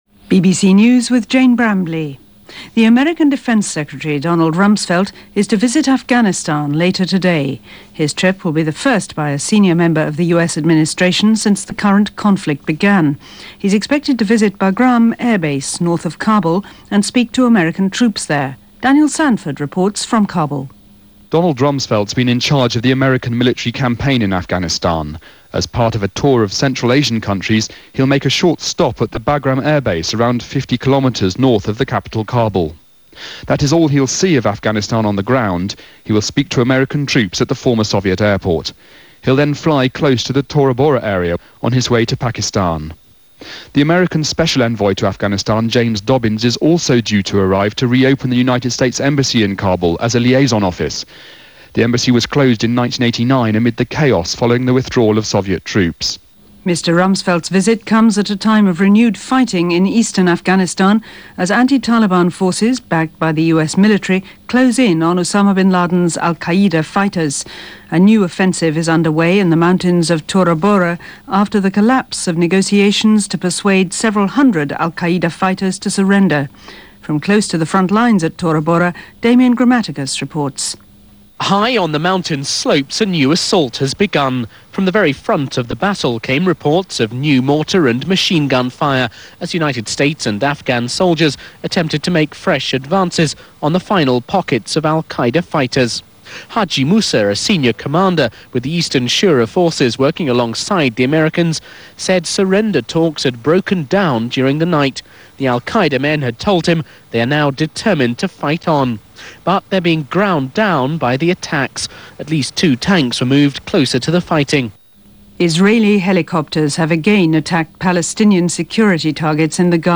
BBC World Service News + From Our Own Correspondent – December 16, 2001 – BBC World Service